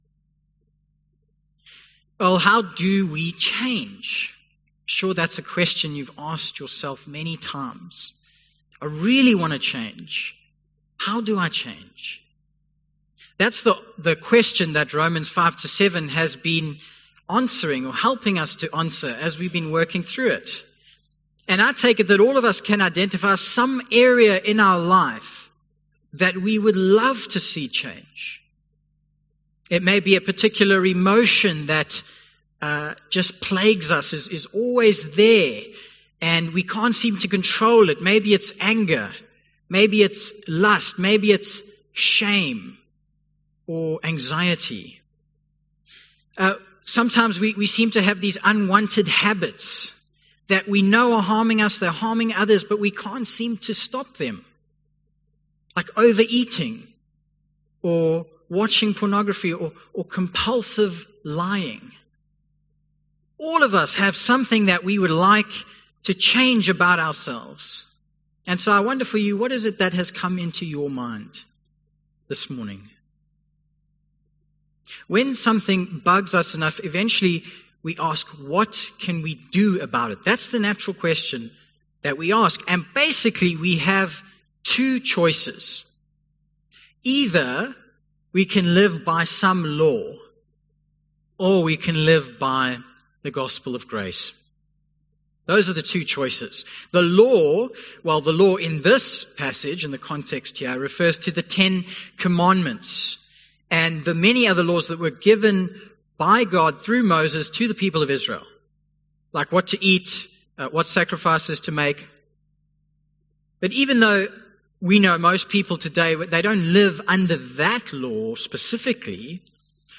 Passage: Romans 7:1-13 Event: English Sermon Topics